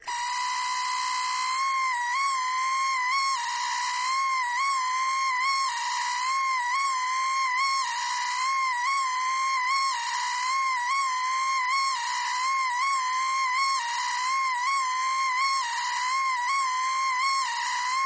Screaming Bering Buzz